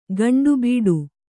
♪ gaṇḍu bīḍu